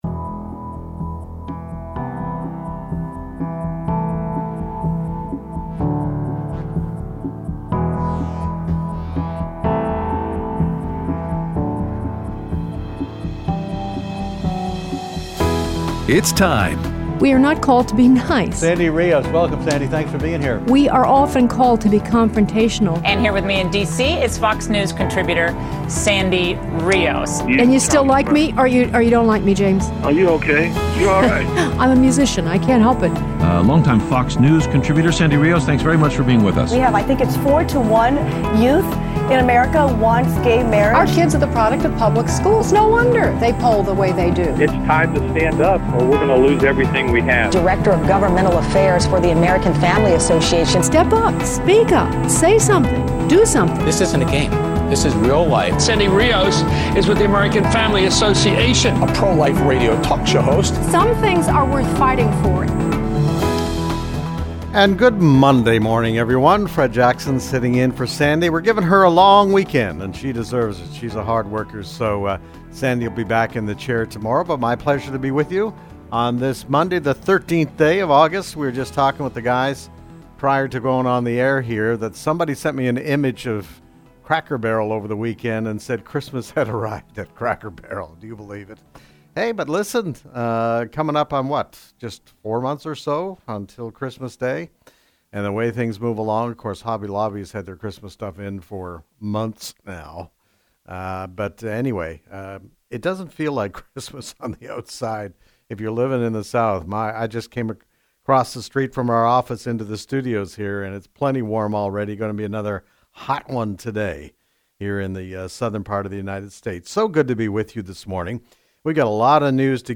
Guest Host
Aired Monday 8/13/18 on AFR 7:05AM - 8:00AM CST